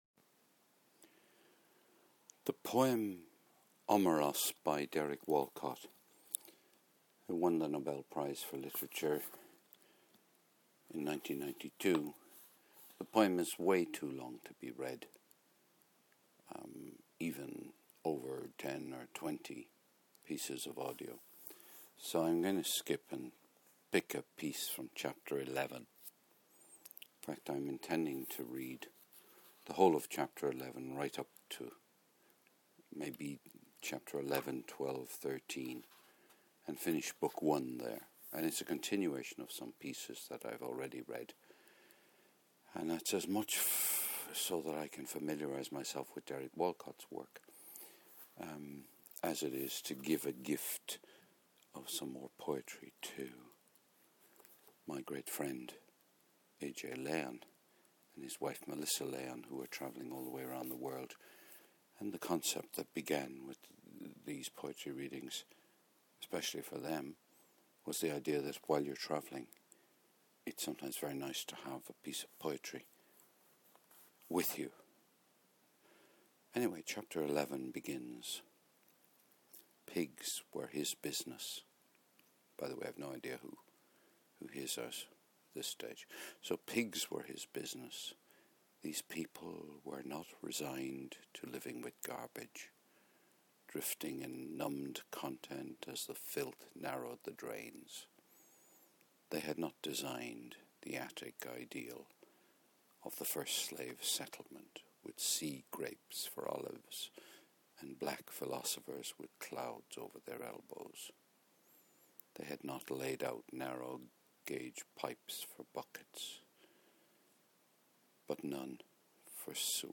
Reading more "Omeros" by Derek Walcott [20 minutes]